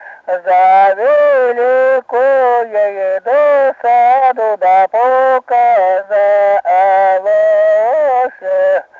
Произнесение частицы –ся в возвратных формах глаголов как –се